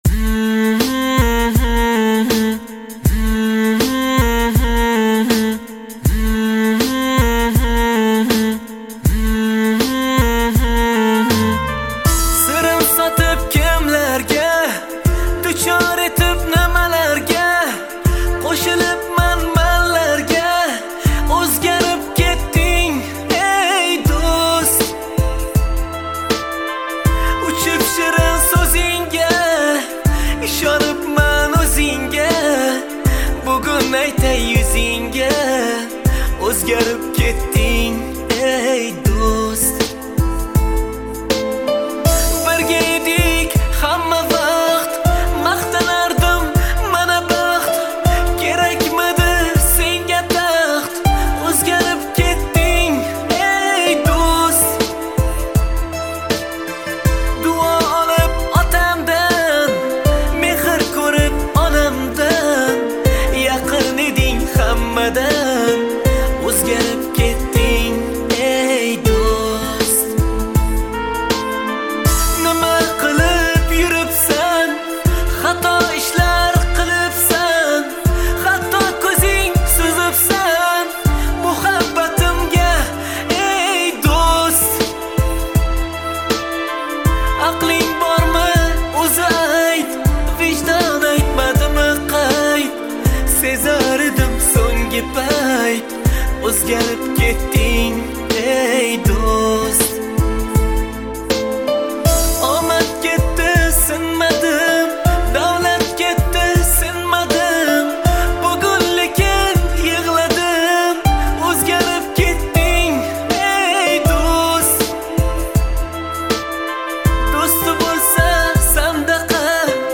Мусиқа ва тарона Ўзбекистон мусиқаси